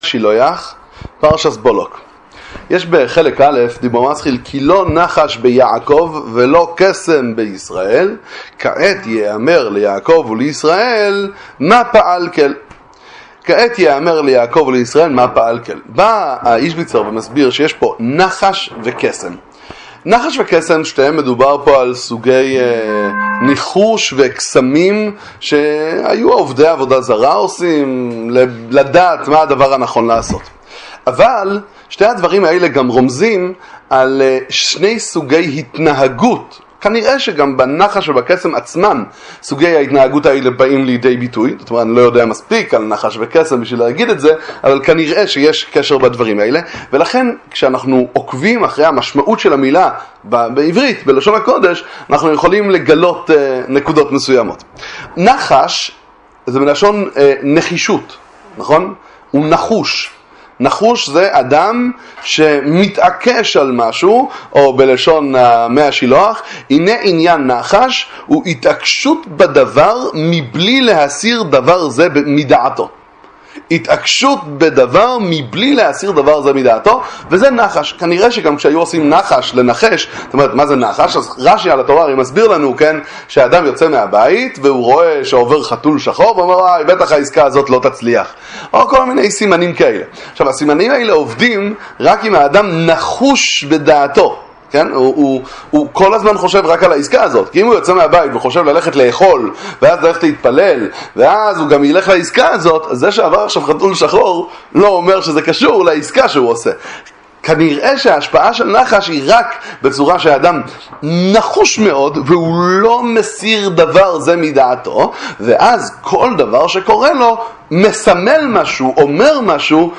נחישות וזרימה... הדרך לחיות עם לב - לימוד מספר מי השילוח איז'ביצא על פרשת בלק